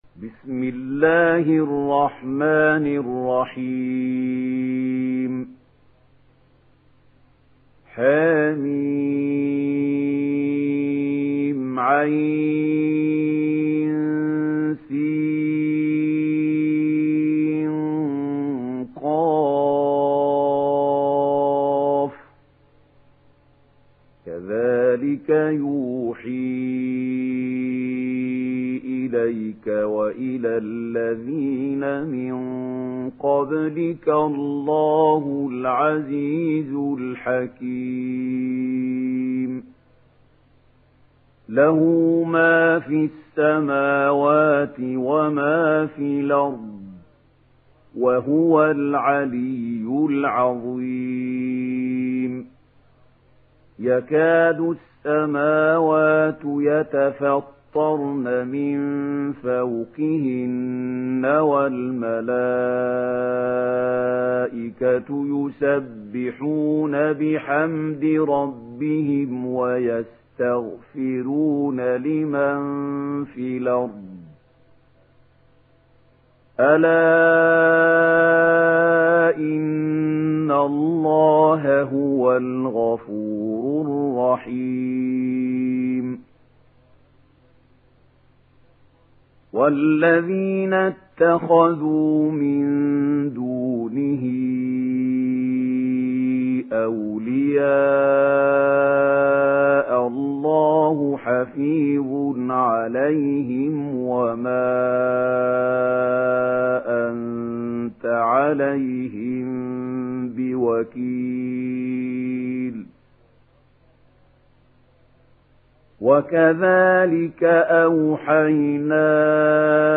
دانلود سوره الشورى mp3 محمود خليل الحصري روایت ورش از نافع, قرآن را دانلود کنید و گوش کن mp3 ، لینک مستقیم کامل